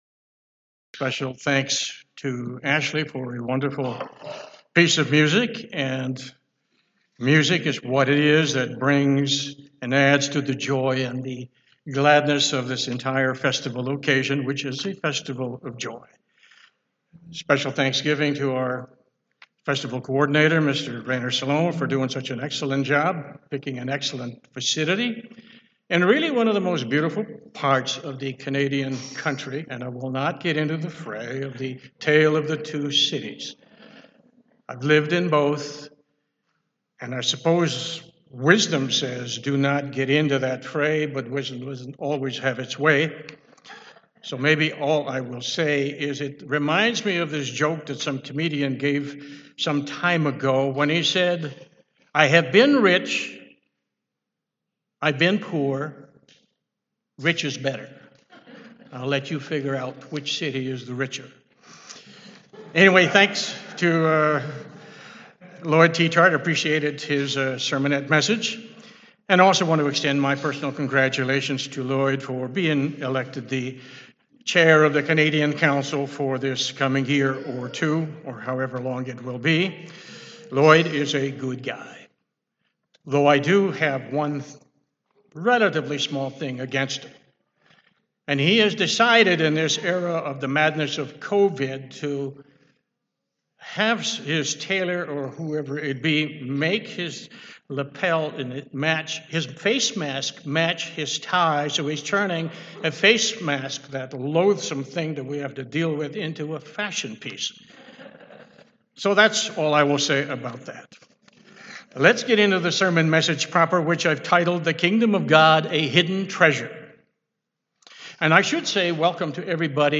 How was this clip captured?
This sermon was given at the Cochrane, Alberta 2020 Feast site.